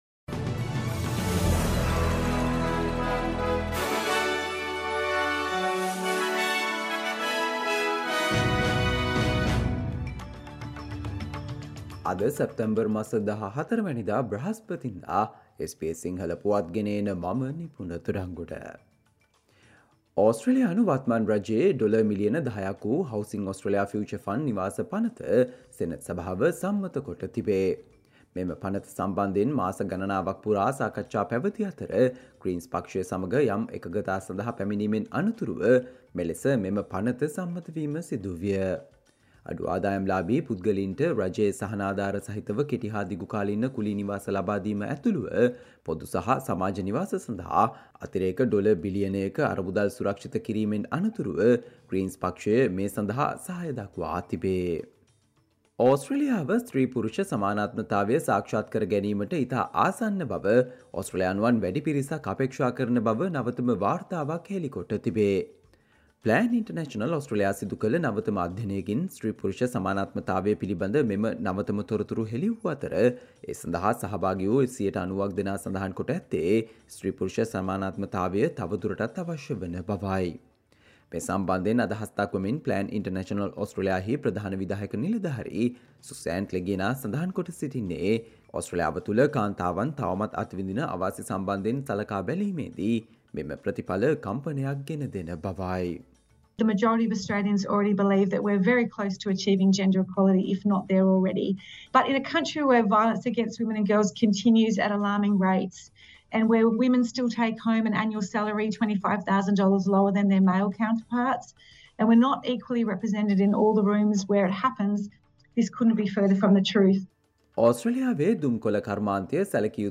Australia news in Sinhala, foreign and sports news in brief - listen, today - Thursday 14 September 2023 SBS Radio News